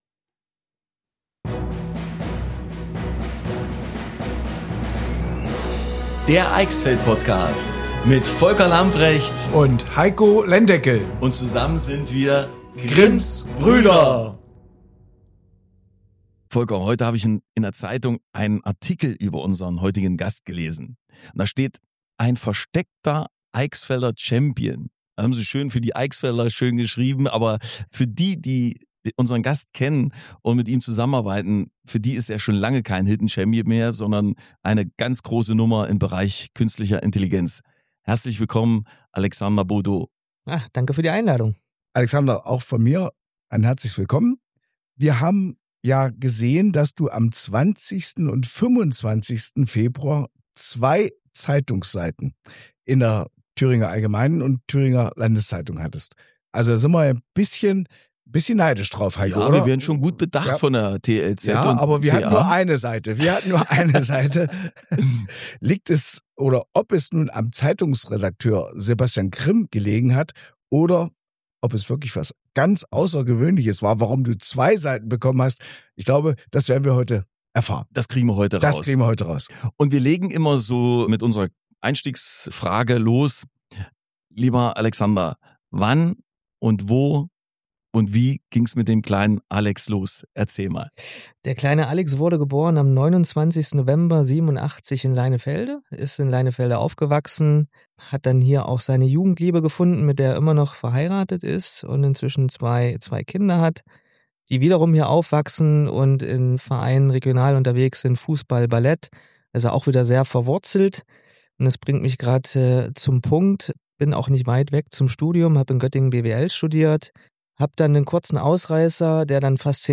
Seine ruhige und sympathische Art gibt dem Gespräch eine besondere Leichtigkeit.